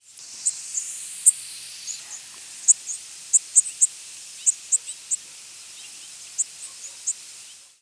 Worm-eating Warbler diurnal flight calls
Diurnal calling sequences: